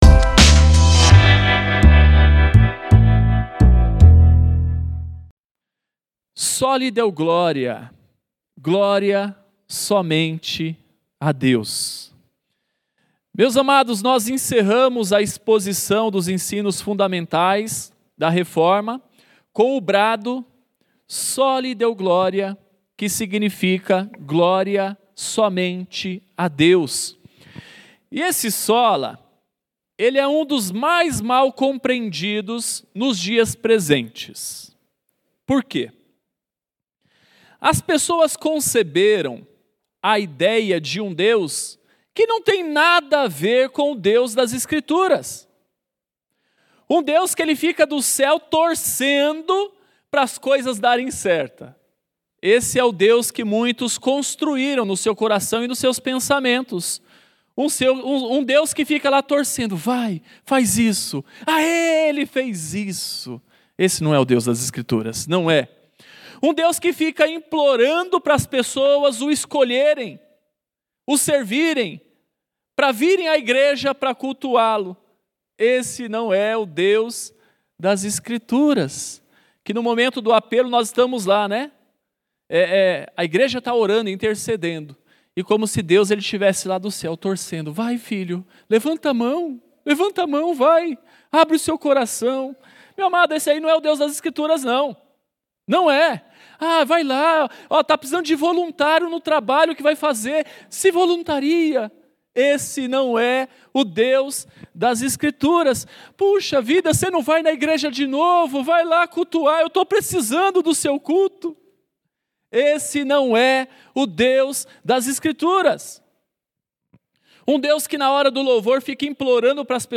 Segunda mensagem da série FÉ REFORMADA